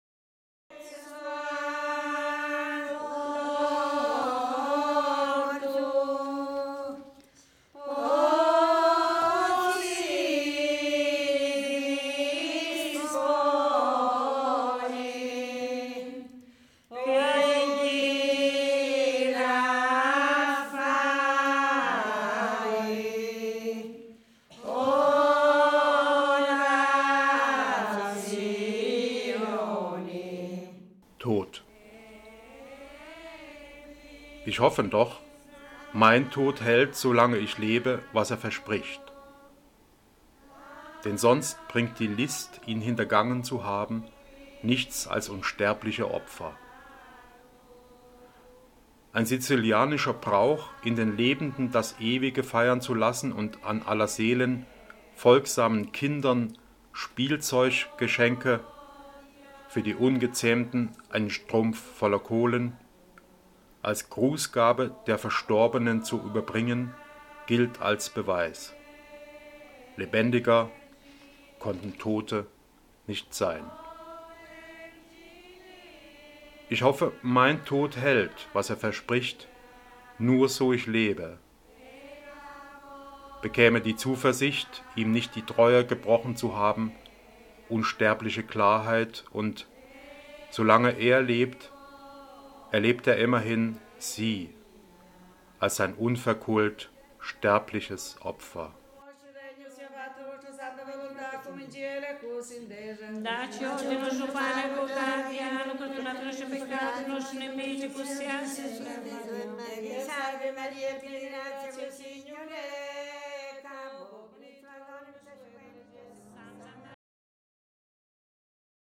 In seiner Stimme gesprochen vernehmen wir es hier: